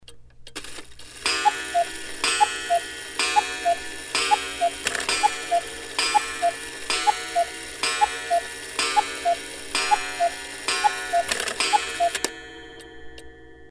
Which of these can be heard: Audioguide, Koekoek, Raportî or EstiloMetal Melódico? Koekoek